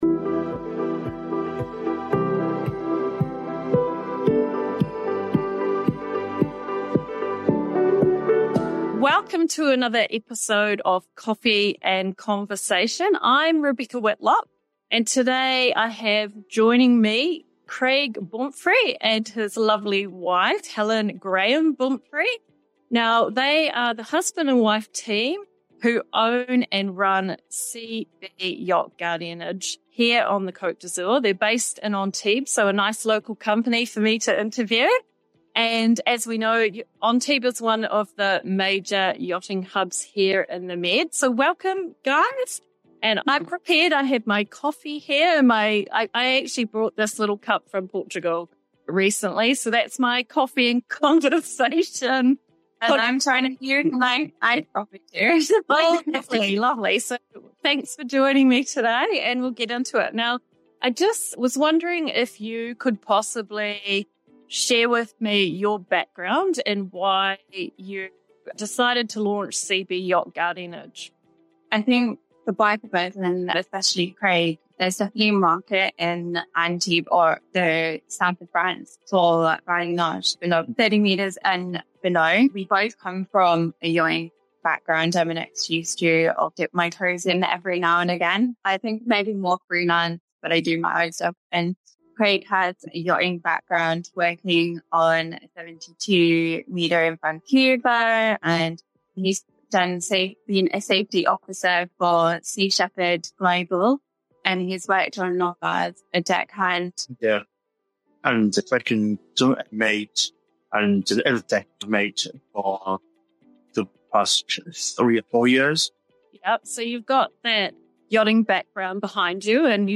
Discover how CB Yacht Guardianage is revolutionizing the yachting experience with tailored packages and unwavering dedication to client satisfaction. Plus, learn about their commitment to mental health awareness through initiatives like the Blue Ducks Foundation. Don't miss this enlightening conversation with industry leaders!